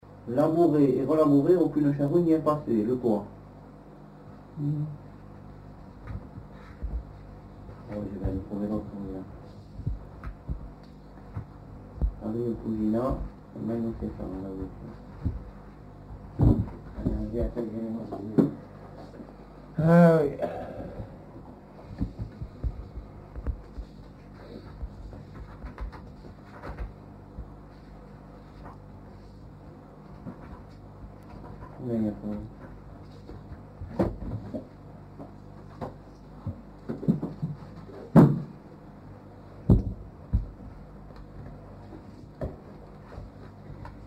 Aire culturelle : Comminges
Lieu : Montauban-de-Luchon
Genre : forme brève
Effectif : 1
Type de voix : voix d'homme
Production du son : récité
Classification : devinette-énigme